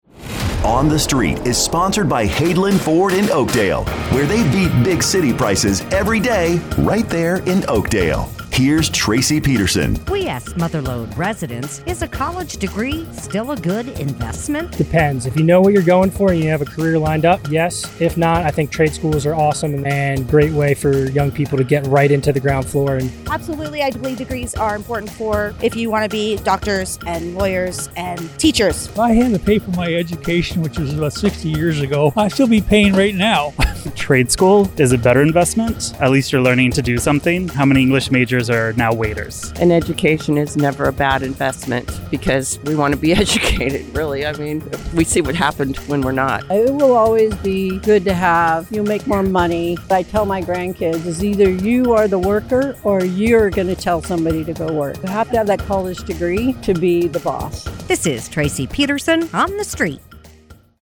asks Mother Lode residents, “Is a college degree still a good investment?”